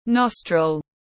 Nostril /ˈnɒs.trəl/